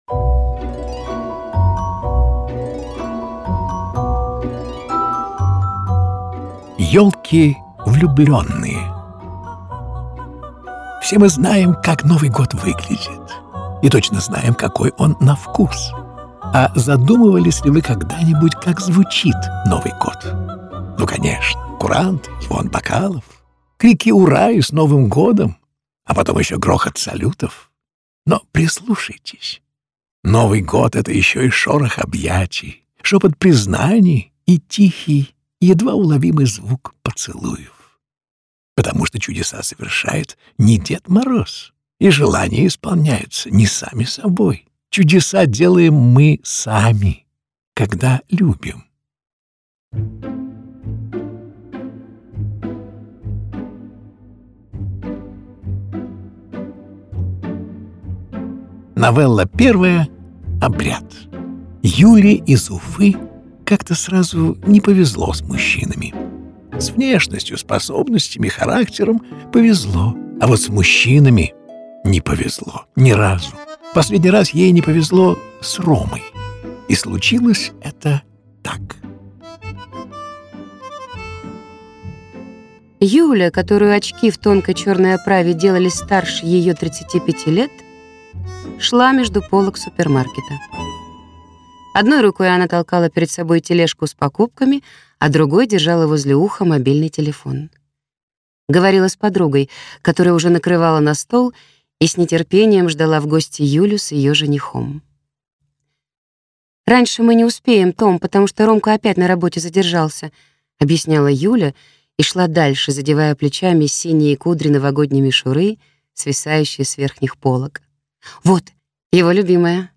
Аудиокнига Ёлки Влюбленные | Библиотека аудиокниг